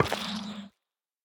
Minecraft Version Minecraft Version snapshot Latest Release | Latest Snapshot snapshot / assets / minecraft / sounds / block / sculk_shrieker / place4.ogg Compare With Compare With Latest Release | Latest Snapshot